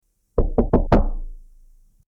Knocking On Door
Knocking_on_door.mp3